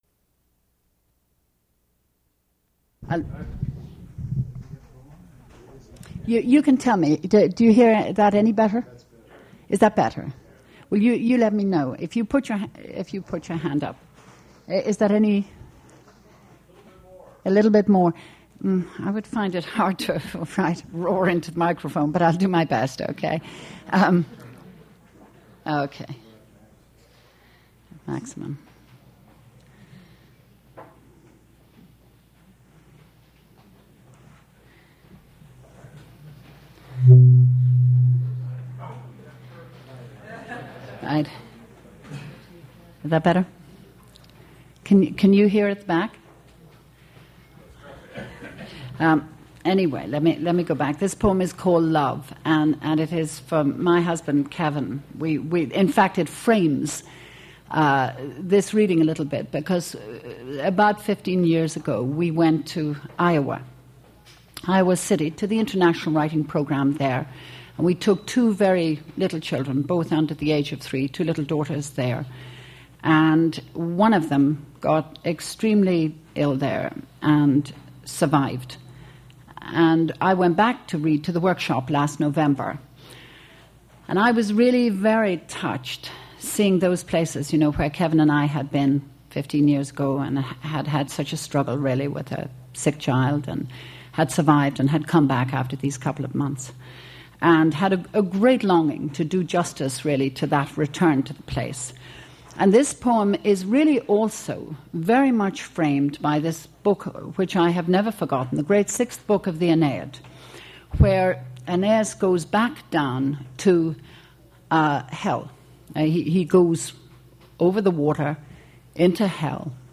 Poetry reading featuring Eavan Boland
Attributes Attribute Name Values Description Eavan Boland poetry reading at Duff's Restaurant.
mp3 edited access file was created from unedited access file which was sourced from preservation WAV file that was generated from original audio cassette.